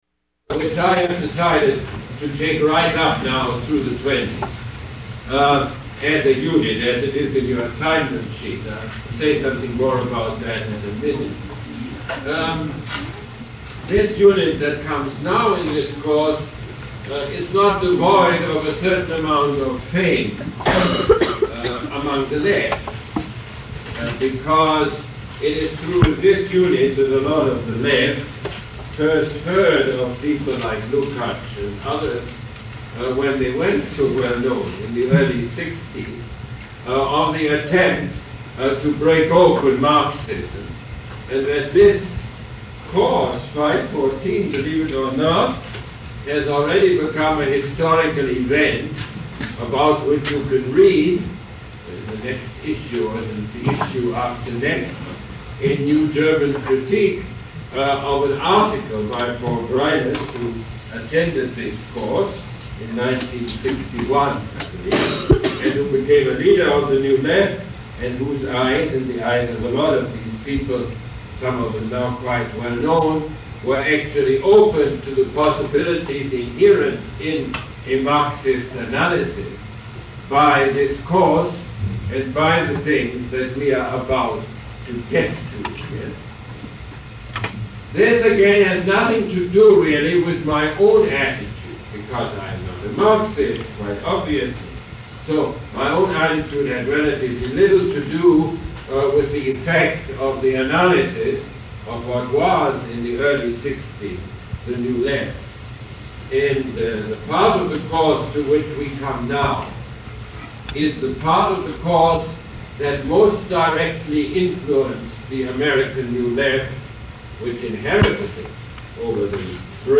Lecture #15 - October 29, 1979